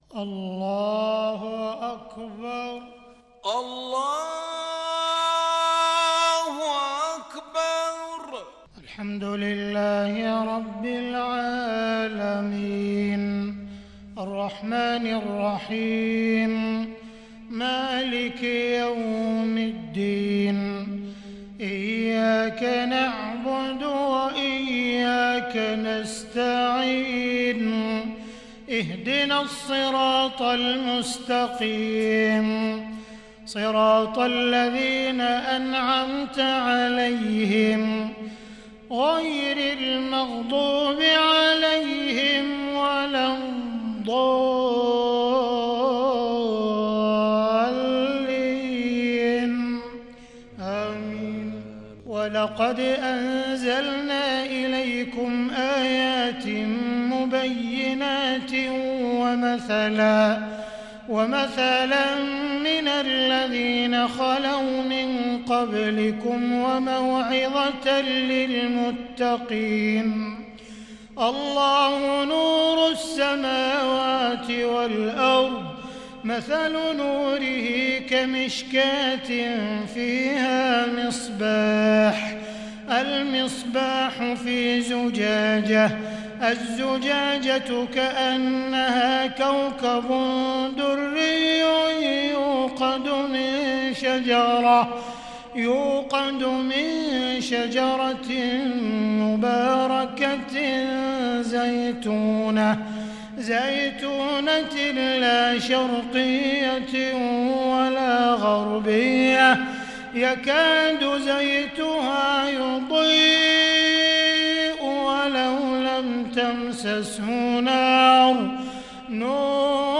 صلاة العشاء للشيخ عبدالرحمن السديس 7 ربيع الآخر 1442 هـ
تِلَاوَات الْحَرَمَيْن .